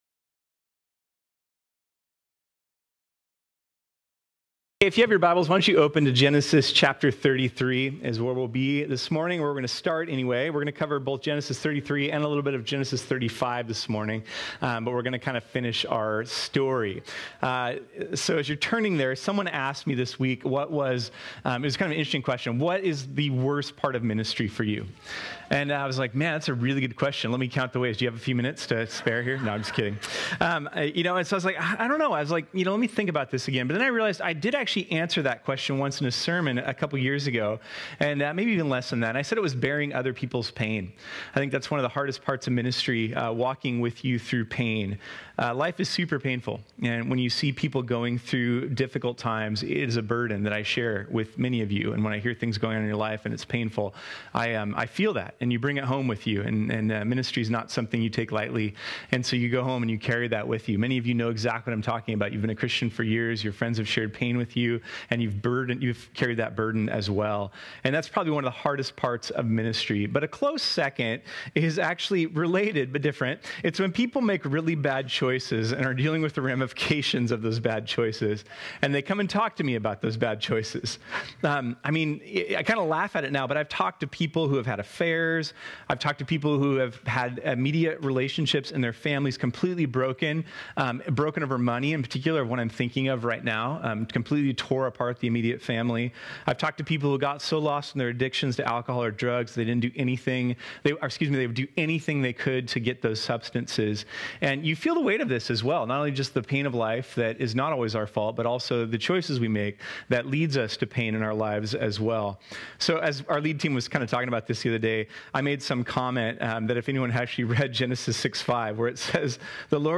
This sermon was originally preached on Sunday, February 5, 2023.